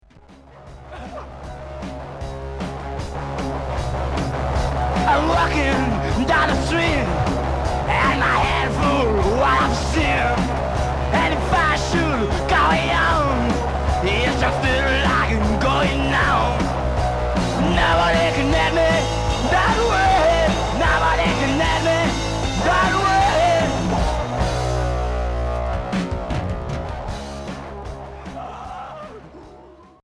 Garage stoogien